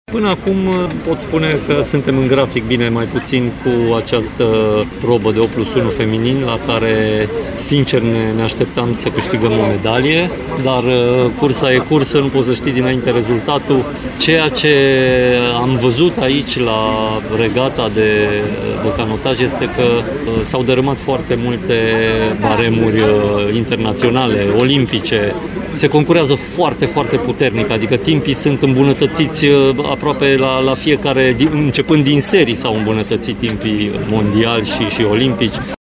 Canotoarele române au avut un timp cu peste 11 secunde mai slab decât cel realizat în recalificările de miercuri, când au stabilit un nou record mondial al probei (5:52.99).  Preşedintele COSR, Mihai Covaliu, cu un bilanţ intermediar: